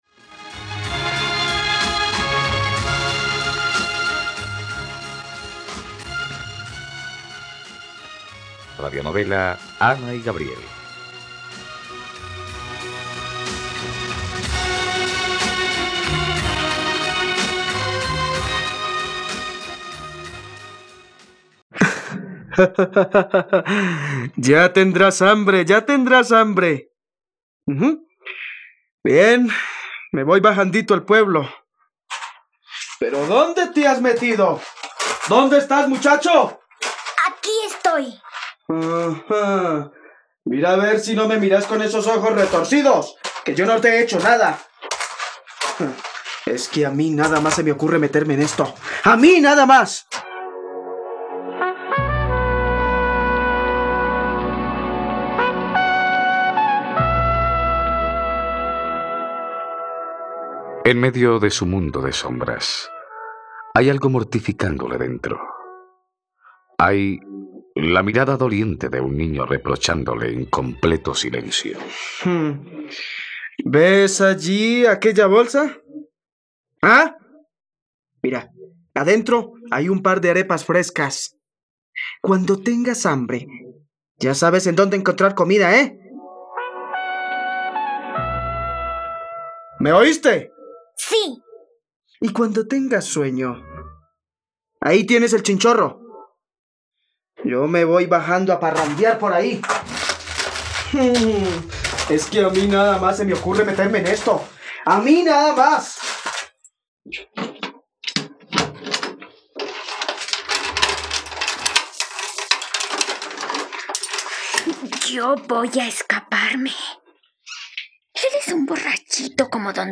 ..Radionovela. Escucha ahora el capítulo 86 de la historia de amor de Ana y Gabriel en la plataforma de streaming de los colombianos: RTVCPlay.